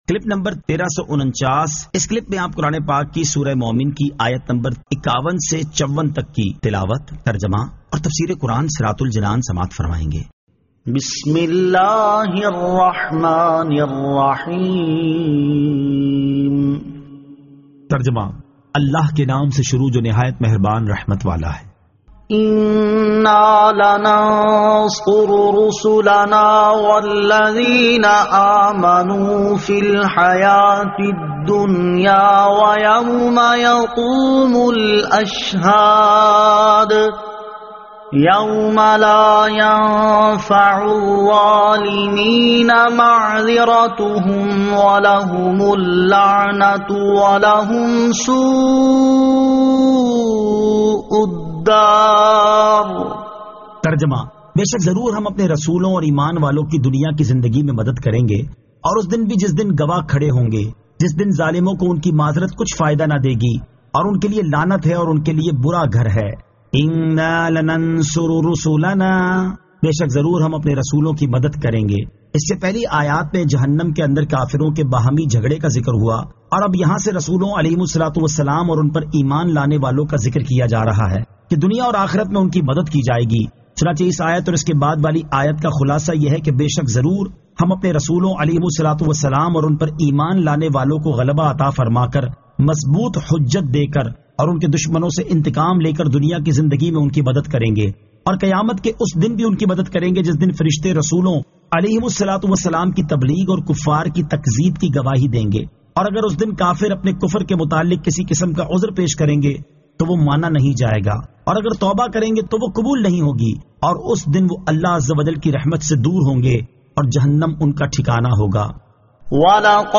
Surah Al-Mu'min 51 To 54 Tilawat , Tarjama , Tafseer